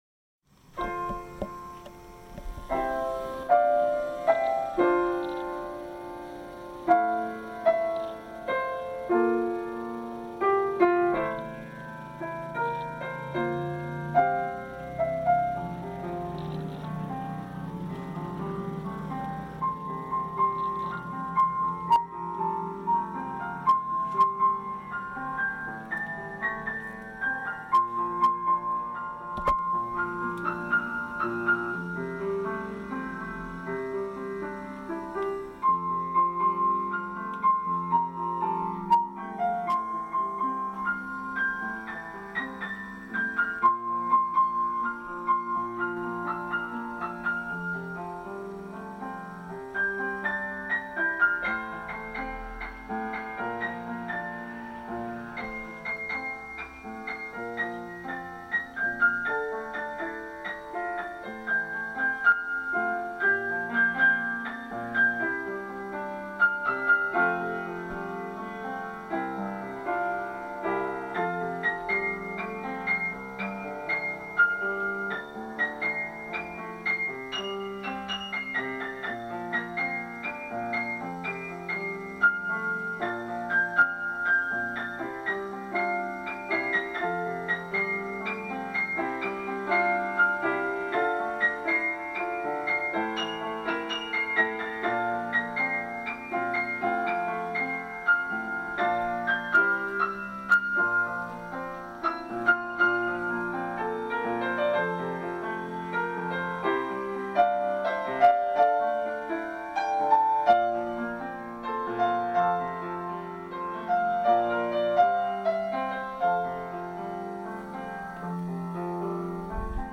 「まだ途中!メロディーと伴奏だけです。」との事だけど、そのピアノのきれいな旋律には思わずうっとり．．。